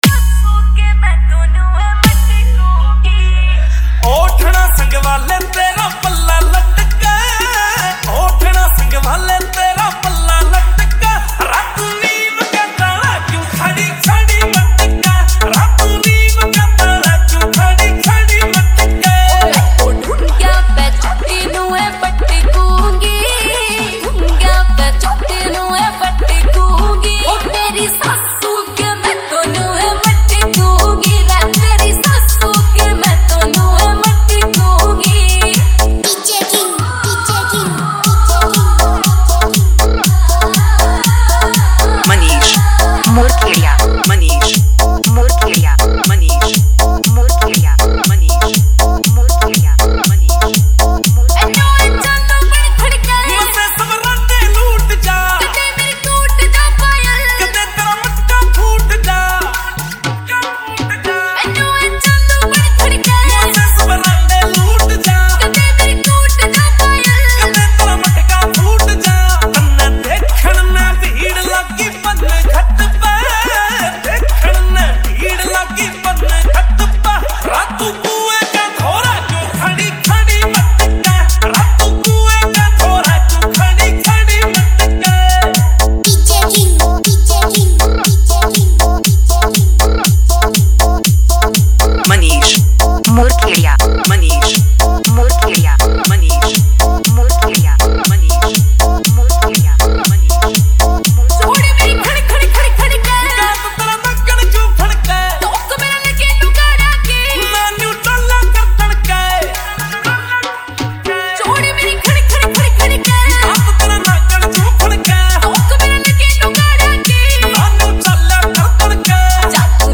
Haryanvi Remix Song